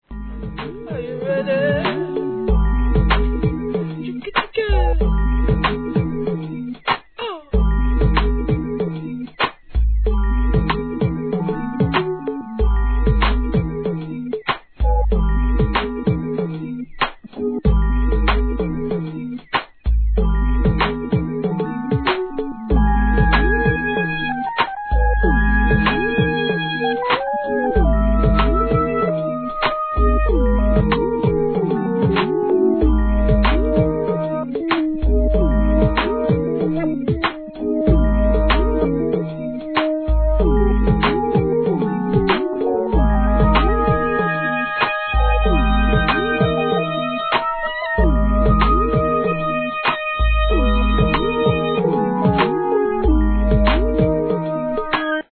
HIP HOP/R&B
優しさの中にもDOPEなHIP HOPを漂わすCHEEPなシンセが怒渋!!